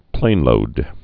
(plānlōd)